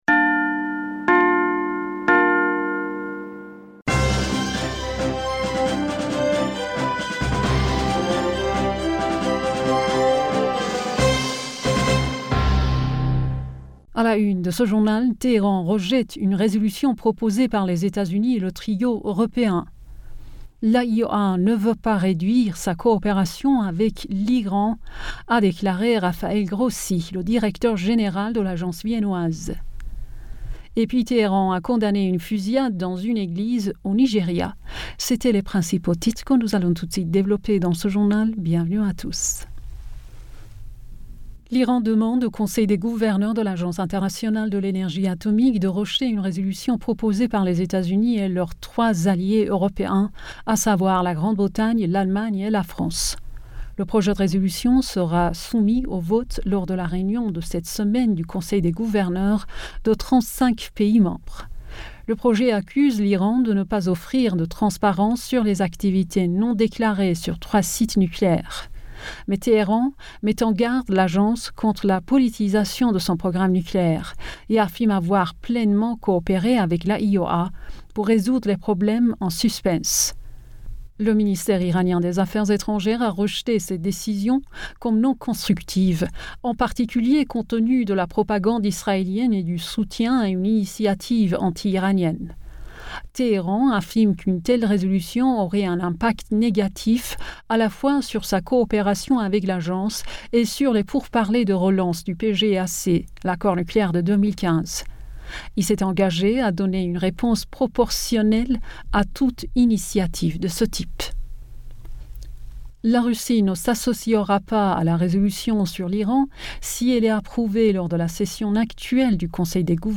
Bulletin d'information Du 07 Juin